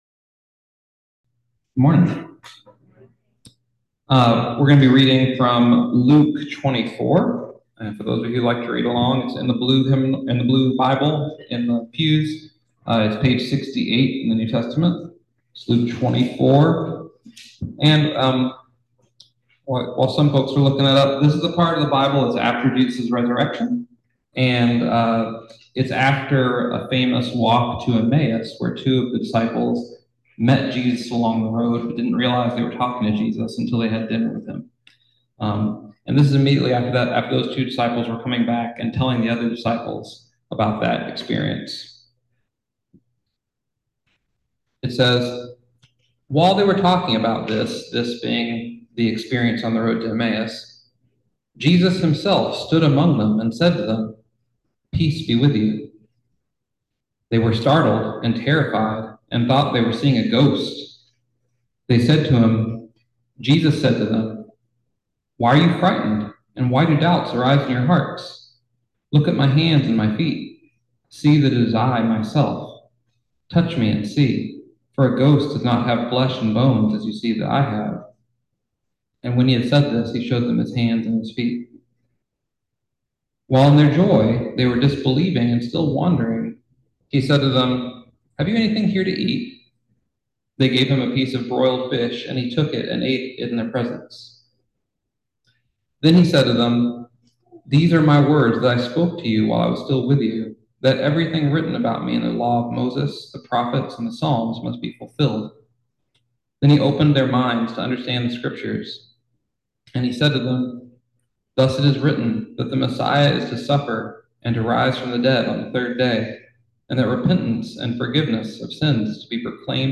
Listen to the most recent message from Sunday worship at Berkeley Friends Church, “You Are Witnesses.”